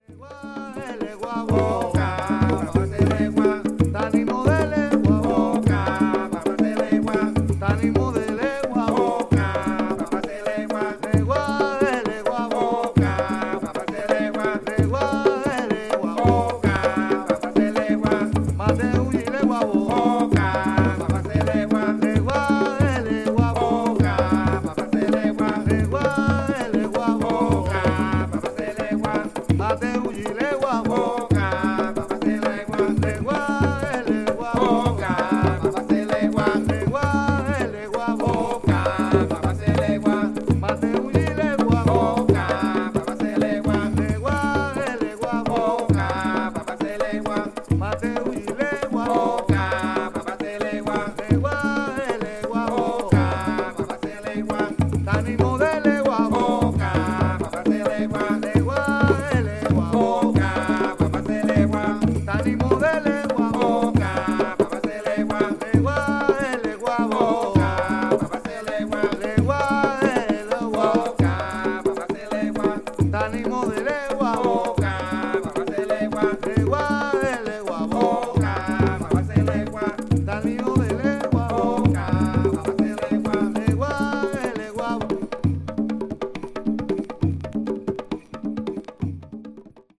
media : EX-/EX-(わずかなチリノイズ/一部軽いチリノイズが入る箇所あり,わずかなプチノイズ数回あり)
パーカッションによるポリリズムとチャントのみによる民族音楽をアレンジしたトラックB1もかなり本格志向です。
Traditional Music
crossover   ethnic jazz   fusion   jazz rock